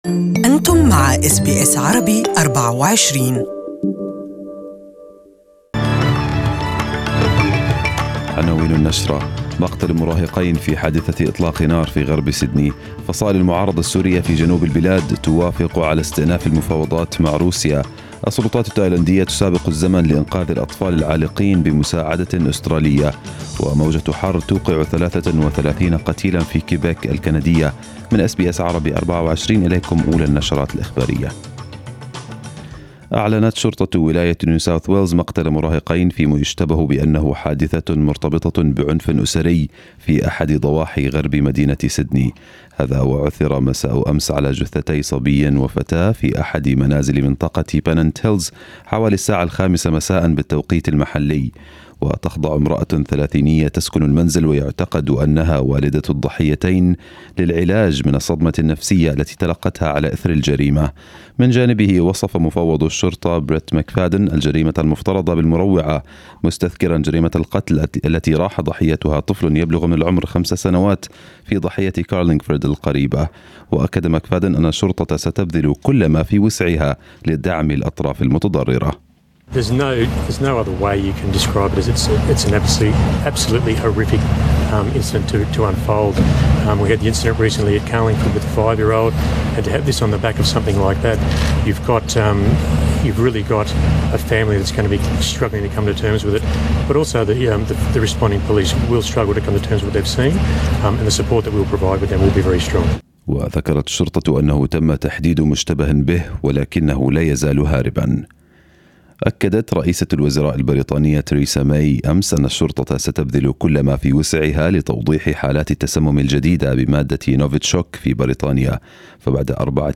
Arabic News Bulletin 06/07/2018